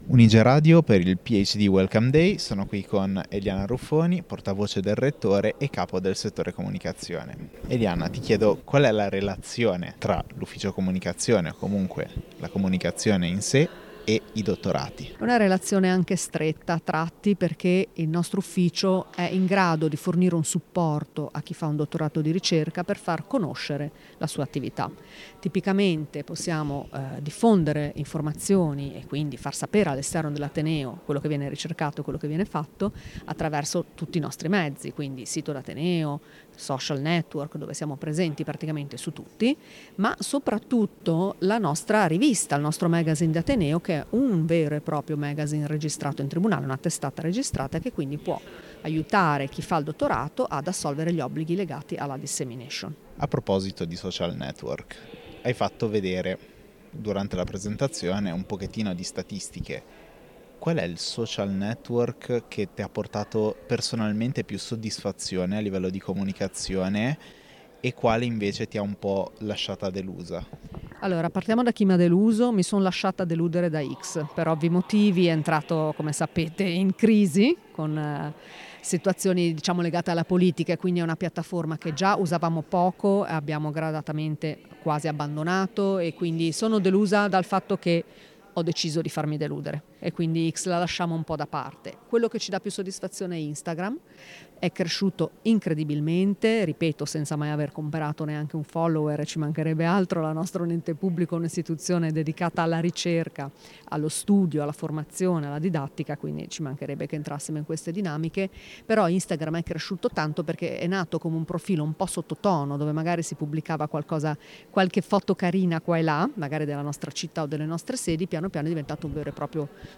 Un’intervista che mette al centro la comunicazione come parte integrante del fare ricerca.